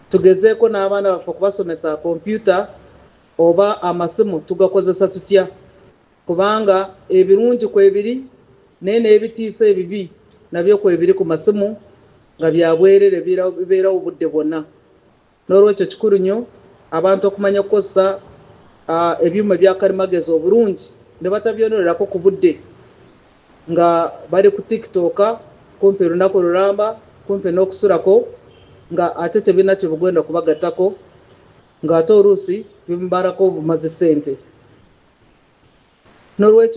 Peace Regis Mutuuzo, minister of State for Gender, In charge of Cultural Affairs addressing journalists at Uganda Media Centre on Tuesday.